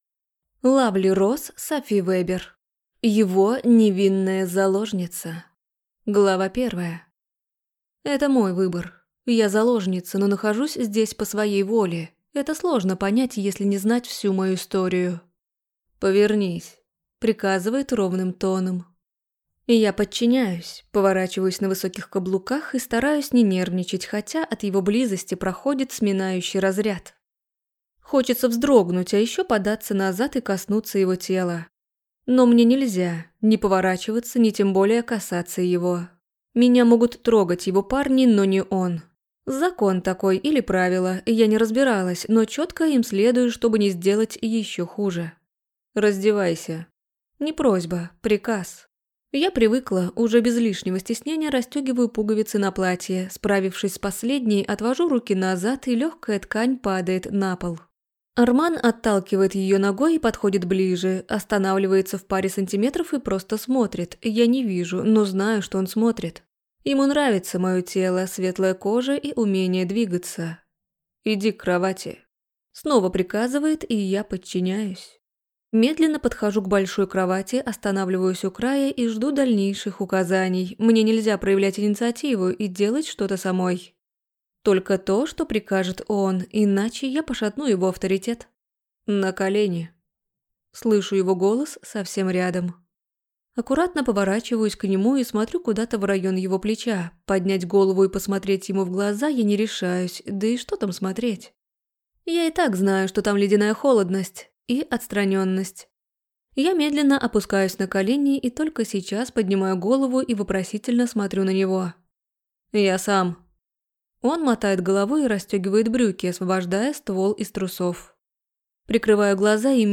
Аудиокнига Его невинная заложница | Библиотека аудиокниг